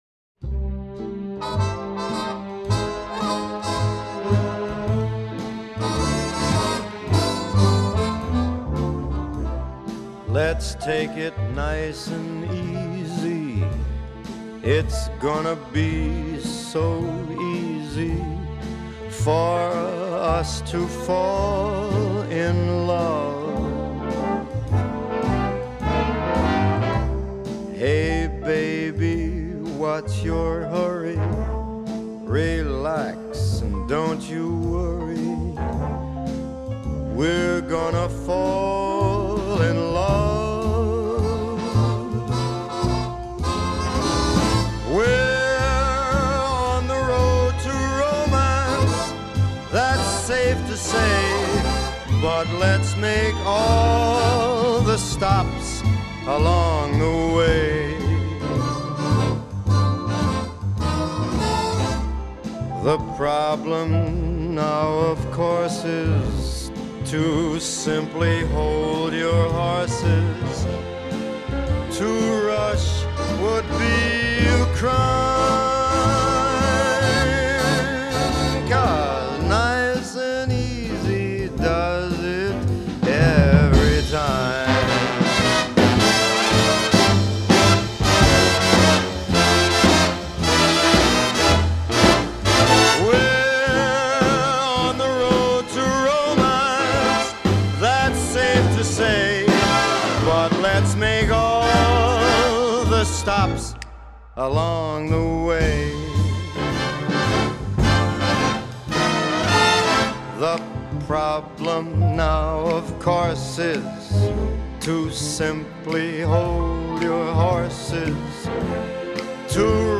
1. The muted trumpets.
2. The strings, hiding way in the background.
3. In the last verse, he starts snapping his fingers.